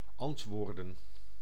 Ääntäminen
IPA: ['ɑnt.ʋɔːɾ.dǝⁿ]